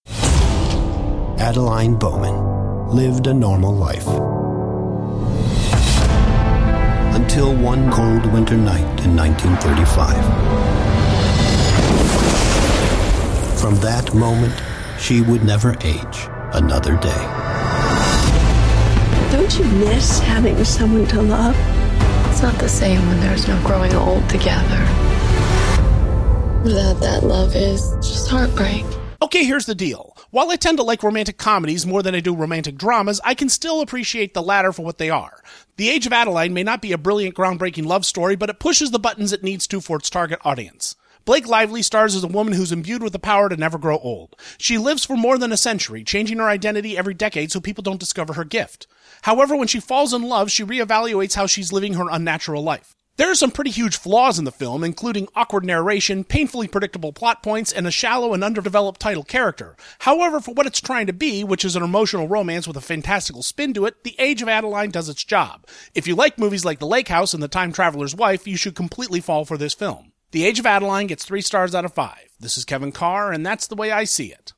Movie Review